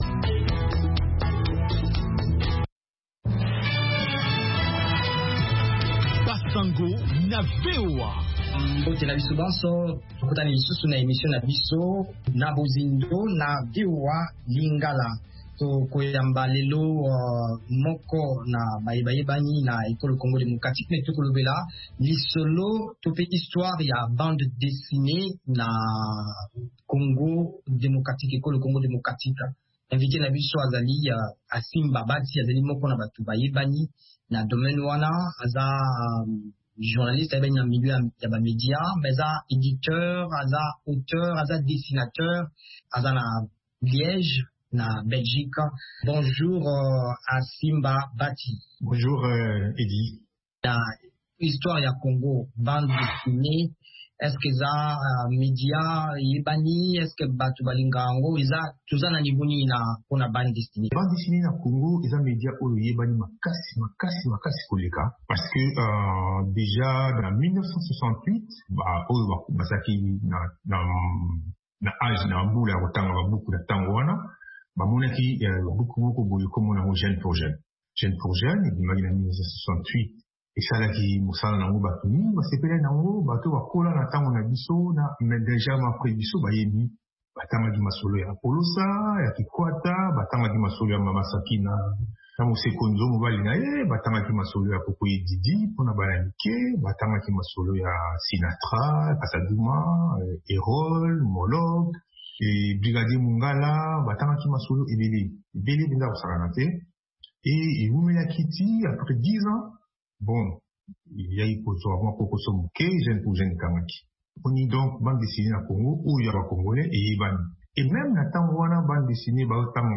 Radio
Basango na VOA Lingala